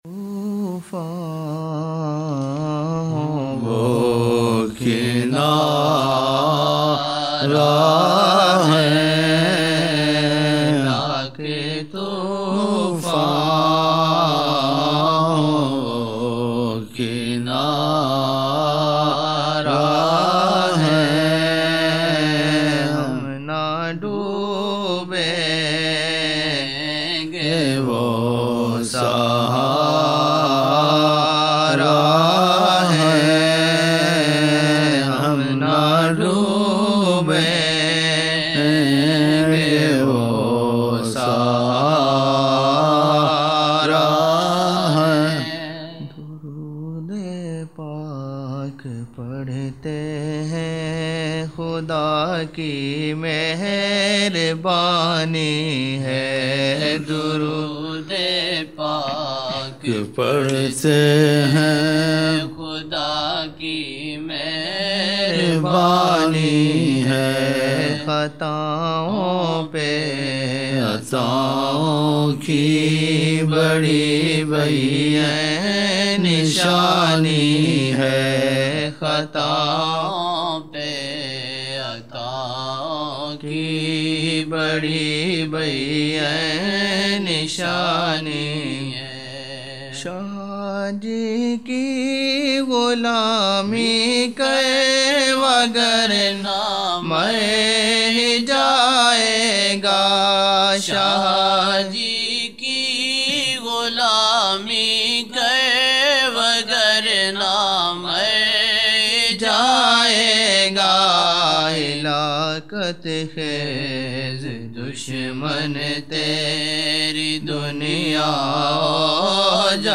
18 November 1999 - Zohar mehfil (10 Shaban 1420)
Naat shareef